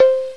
snd_1081_108 Kalimba (C4).wav